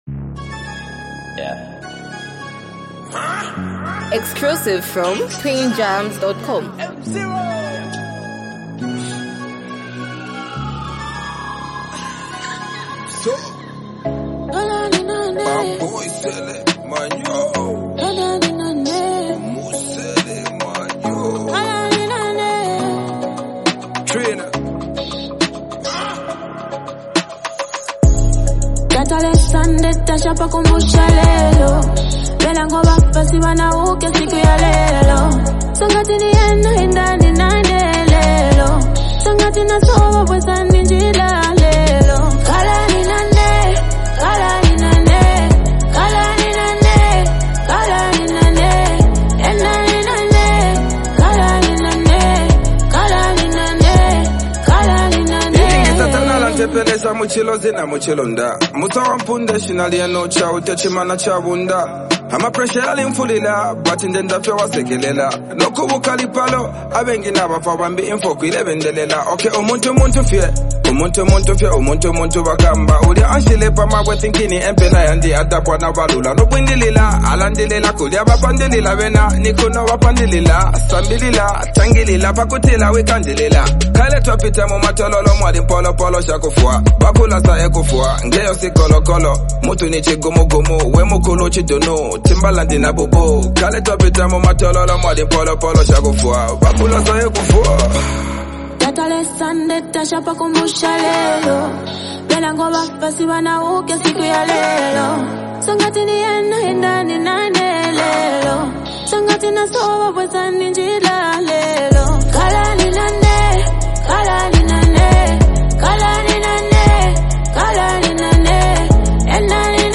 soulful vocals
Through its heartfelt lyrics and gentle melody